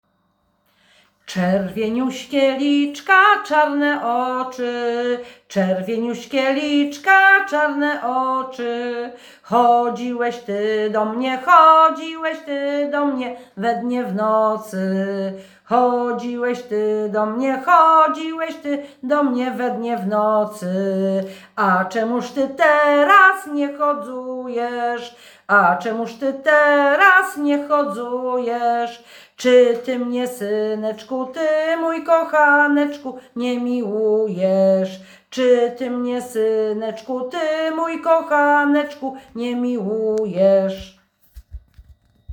Czerwieniuśkie liczka, czarne oczy – Żeńska Kapela Ludowa Zagłębianki
Nagranie współczesne